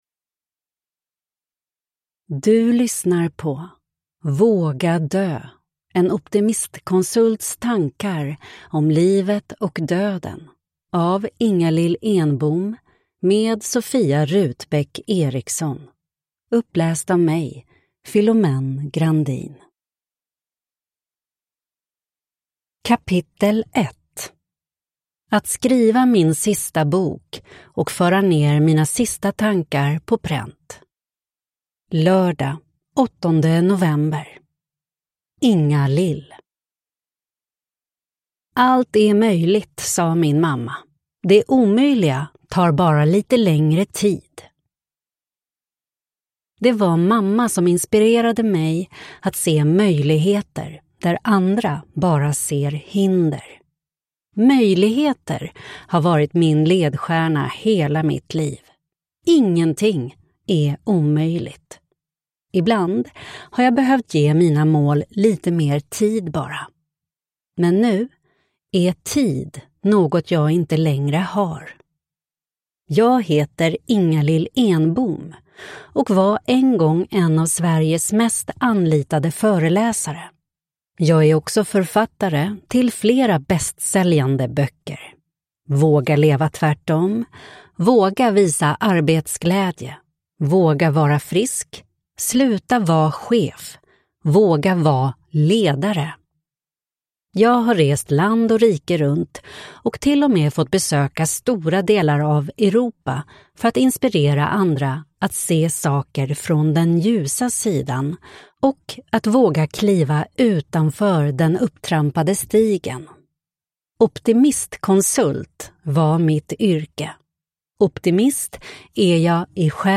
Våga dö: En optimistkonsults tankar om livet och döden (ljudbok